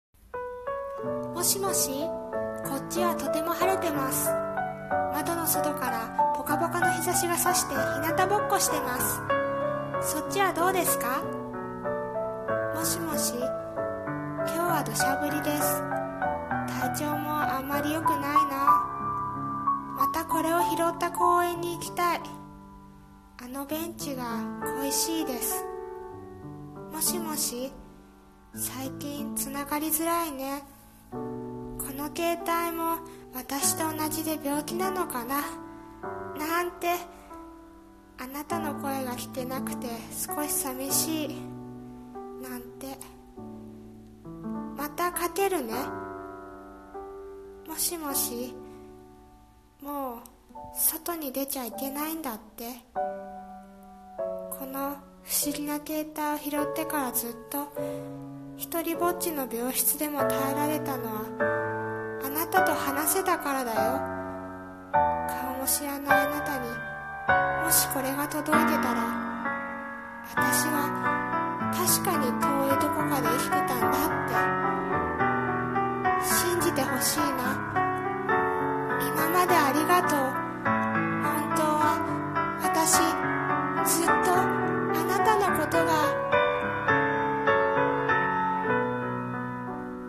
さんの投稿した曲一覧 を表示 【一人声劇】最期の留守電【台本】